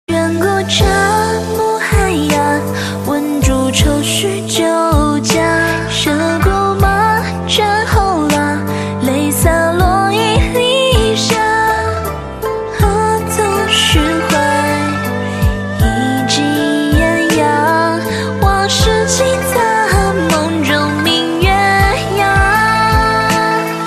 M4R铃声, MP3铃声, 华语歌曲 104 首发日期：2018-05-15 10:21 星期二